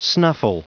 Prononciation du mot snuffle en anglais (fichier audio)
Prononciation du mot : snuffle